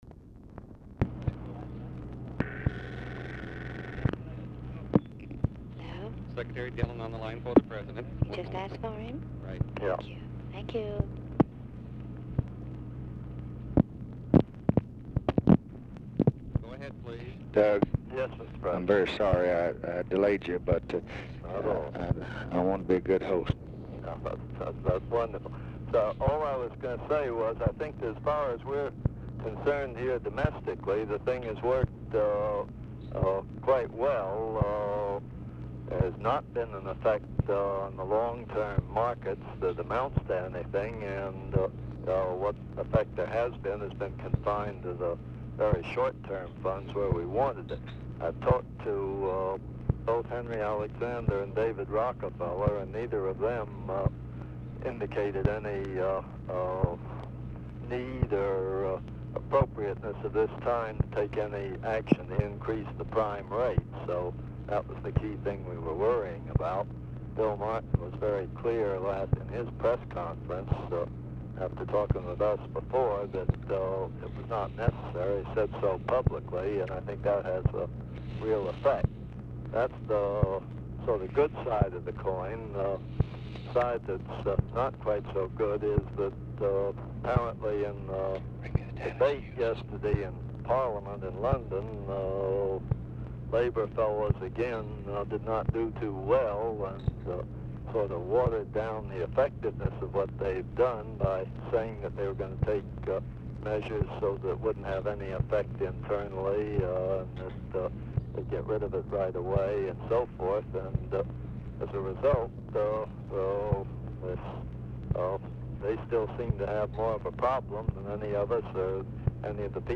Telephone conversation # 6476, sound recording, LBJ and DOUGLAS DILLON, 11/24/1964, 11:14AM | Discover LBJ
Format Dictation belt
Location Of Speaker 1 LBJ Ranch, near Stonewall, Texas
Specific Item Type Telephone conversation